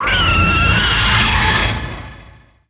horror
Dragon Cry Roar